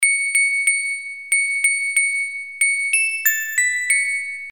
без слов
короткие
колокольчики
звонкие
рождественские
исполненной на треугольнике